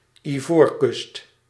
Ääntäminen
IPA: /i.vɔ:ɾ.kʏst/